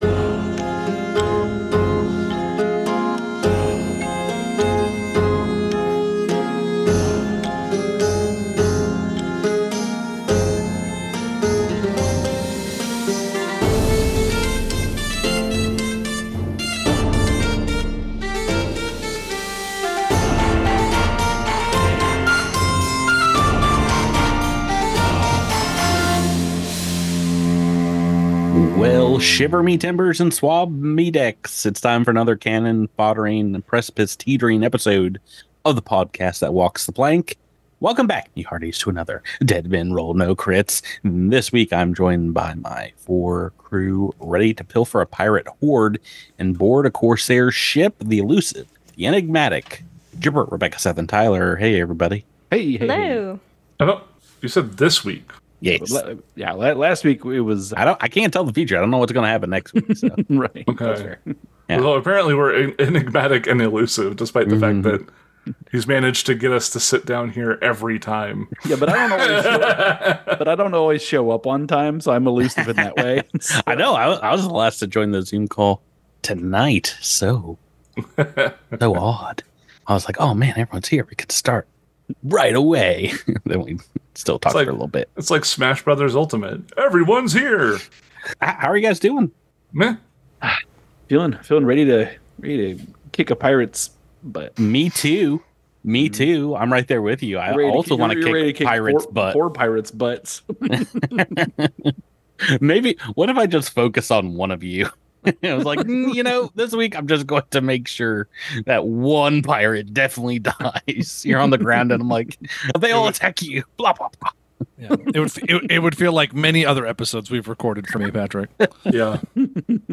About the Podcast Dead Men Roll No Crits is an actual play podcast from the Cosmic Crit network, featuring the beloved Adventure Path “Skull and Shackles,” converted for Pathfinder 2E (Second Edition).